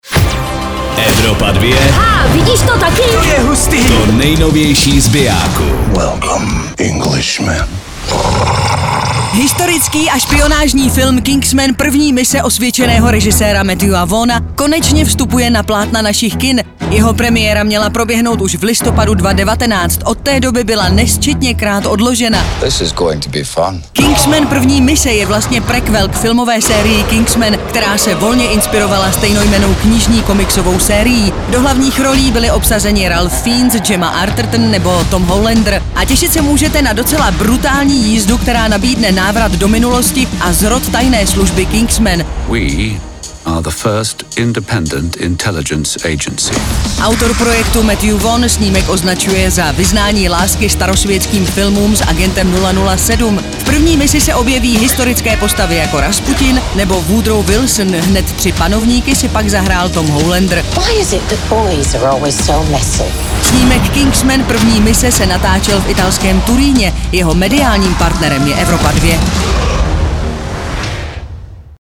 filmový trailer